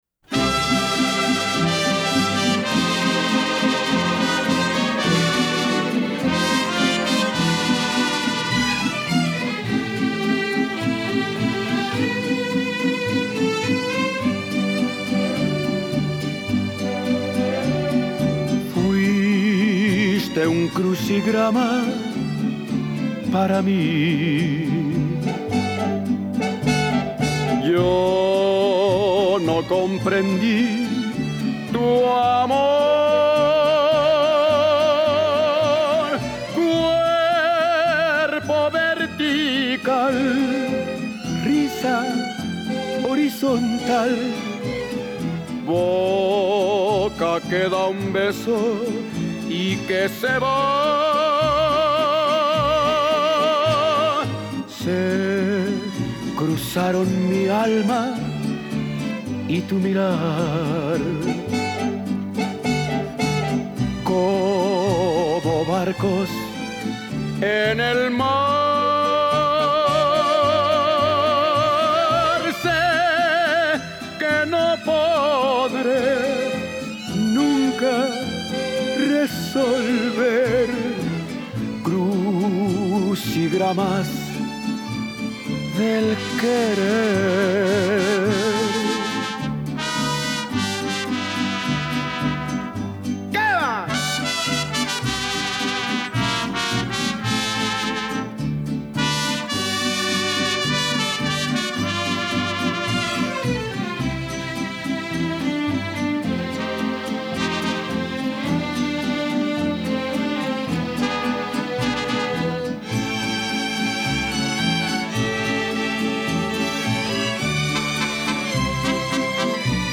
Es, creo yo, una dulce e ingenua interpretación del desamor.
lindo bolerito